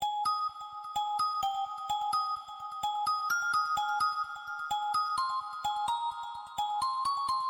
描述：怀旧的声音，用Windows XP的声音制作。
标签： 128 bpm Dance Loops Synth Loops 1.26 MB wav Key : G
声道立体声